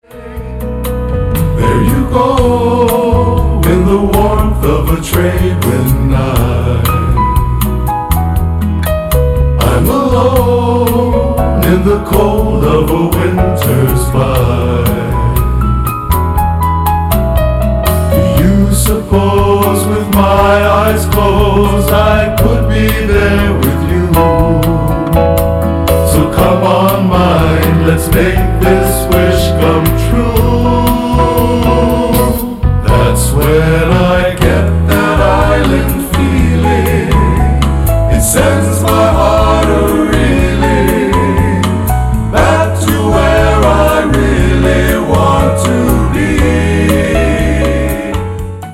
sweet, sweet harmonies
samba-tempoed